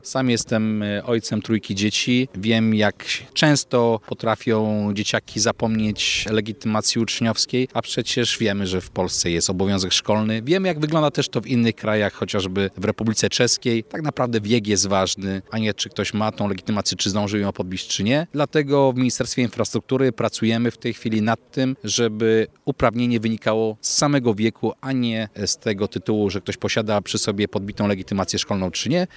Jak zapowiedział wiceminister Przemysław Koperski w rozmowie z Twoim Radiem – kluczowe ma być nie posiadanie dokumentu, a wiek ucznia.